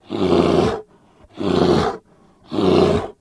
Kodiak Bear Growl Breaths